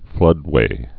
(flŭdwā)